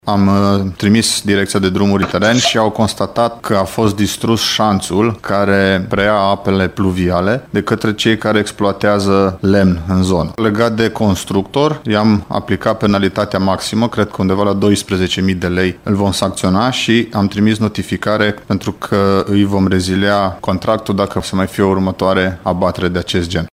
În fapt, mașinile grele ale transportatorilor de lemn din zonă au distrus marginea carosabilului, așa că apa care a coborât de pe versanți s-a acumulat și a înghețat, pe fondul temperaturilor scăzute, spune vicepreședintele Consiliului Județean Timiș, Alexandru Proteasa.